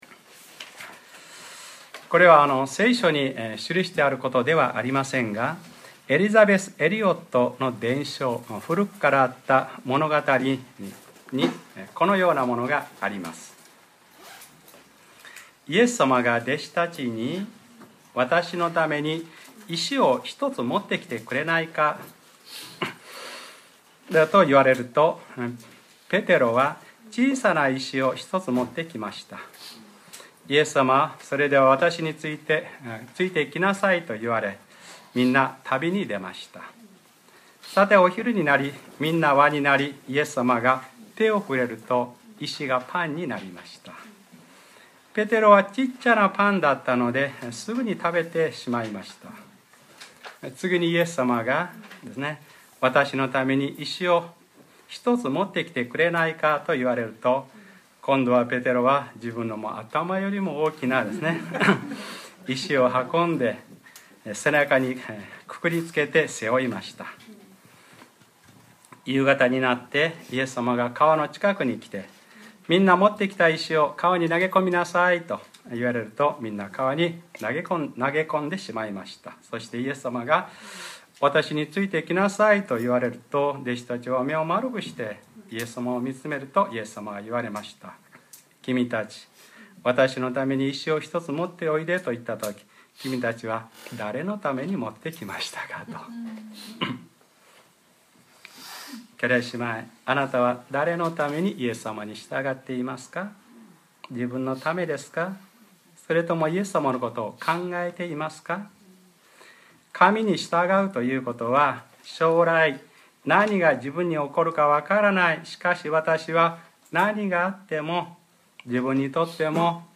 2013年10月27日(日）礼拝説教 『黙示録ｰ２４ 神の激しい怒りの７つの鉢を』